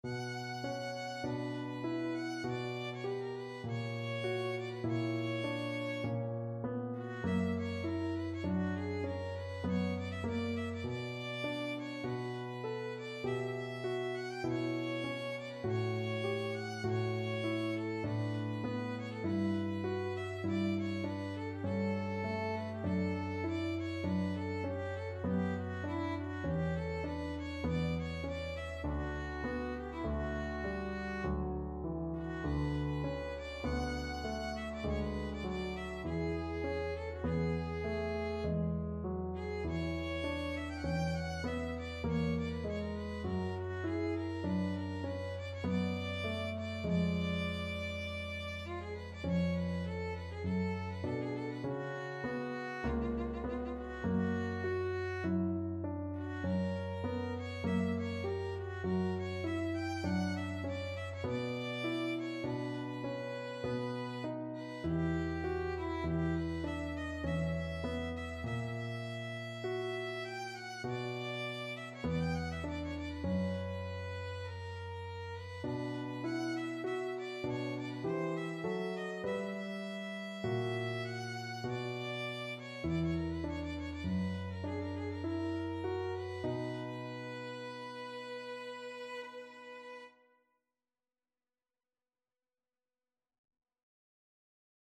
Violin
B minor (Sounding Pitch) (View more B minor Music for Violin )
Largo
3/4 (View more 3/4 Music)
Classical (View more Classical Violin Music)